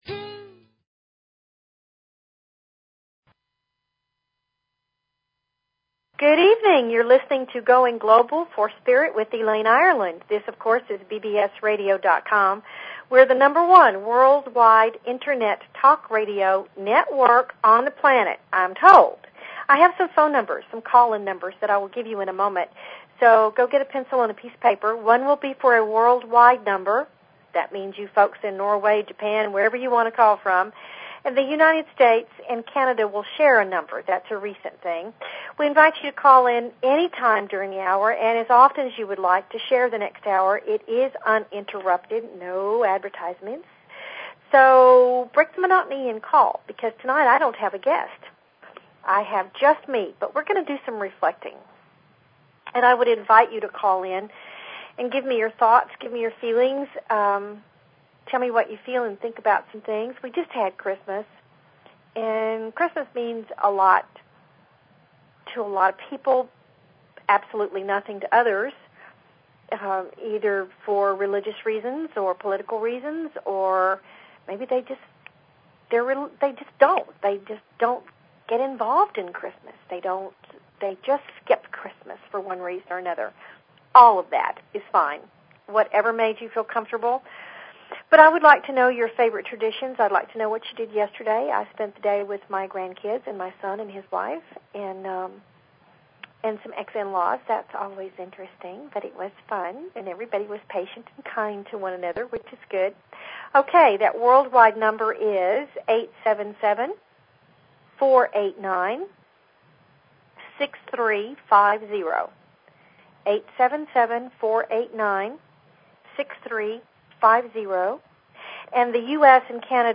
Talk Show Episode, Audio Podcast, Going_Global_for_Spirit and Courtesy of BBS Radio on , show guests , about , categorized as
JUST ME FOR THE NIGHT SO CALL IN WITH YOUR QUESTIONS.